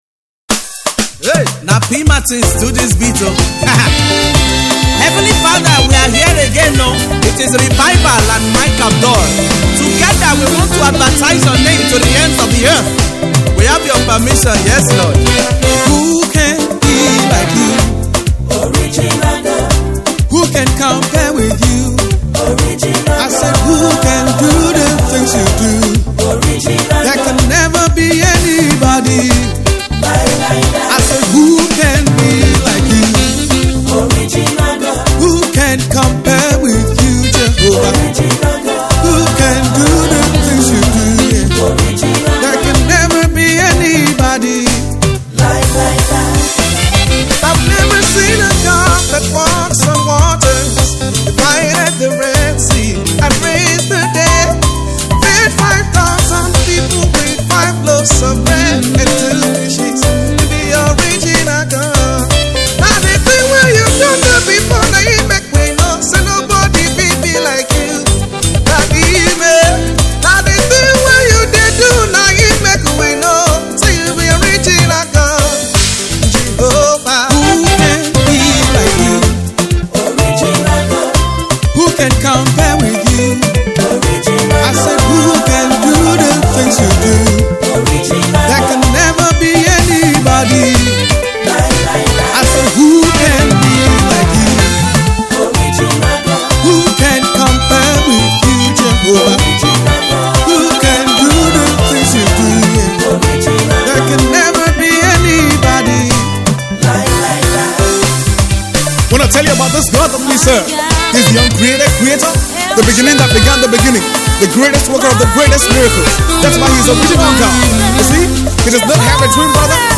its a Solid Gospel Track